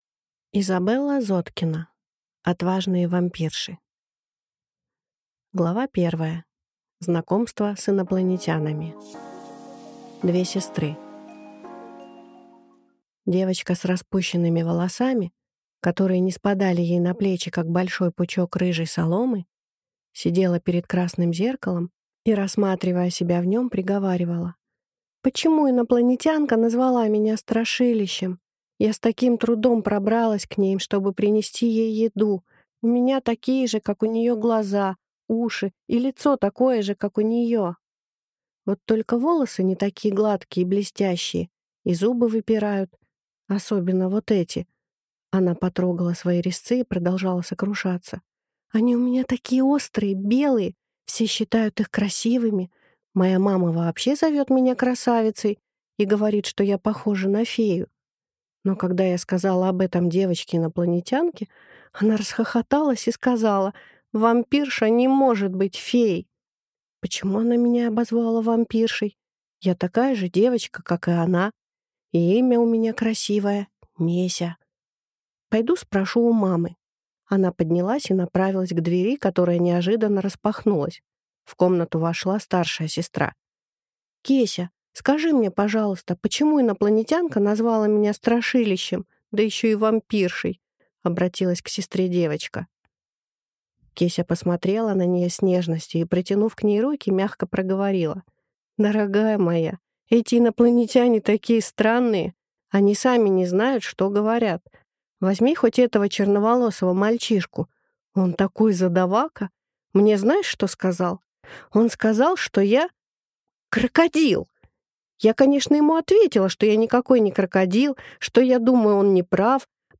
Аудиокнига Отважные Вампирши | Библиотека аудиокниг
Прослушать и бесплатно скачать фрагмент аудиокниги